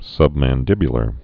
(sŭbmăn-dĭbyə-lər)